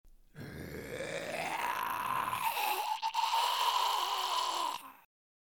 Download Zombie Horde sound effect for free.
Zombie Horde